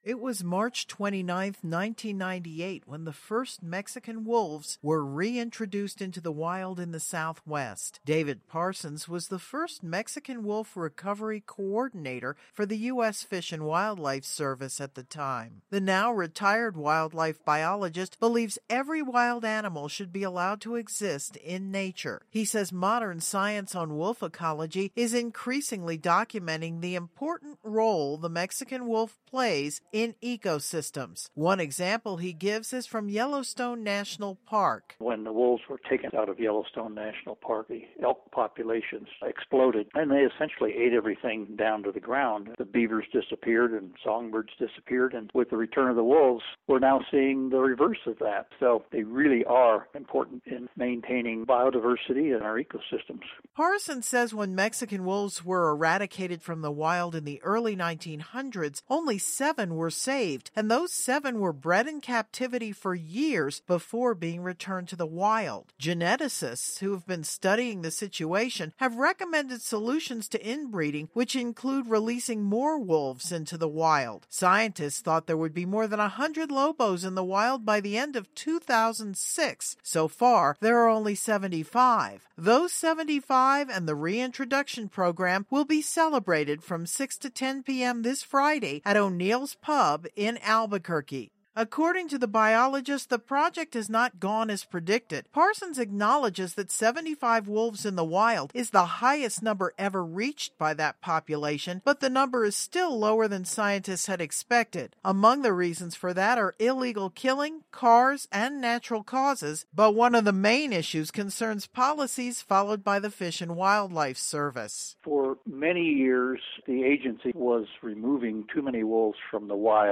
audio broadcast of this story here.